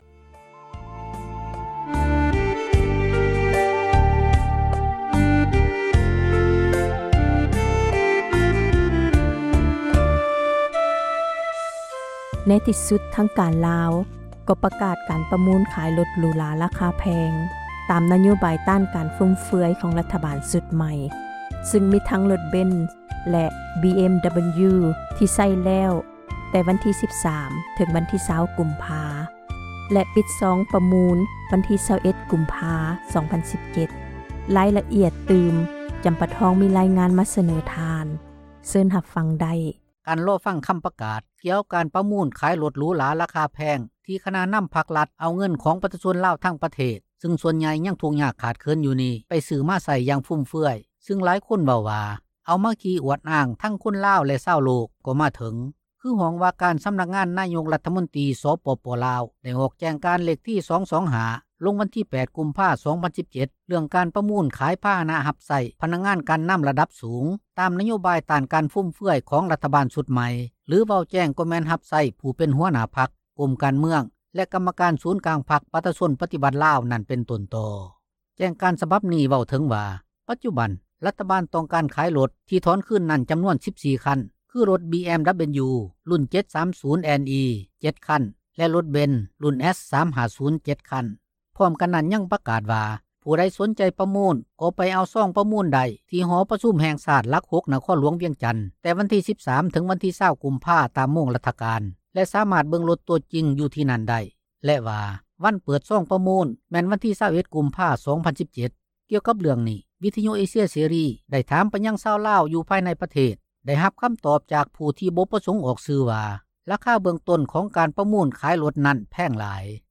ມີຣາຍງານ ມາສເນີທ່ານ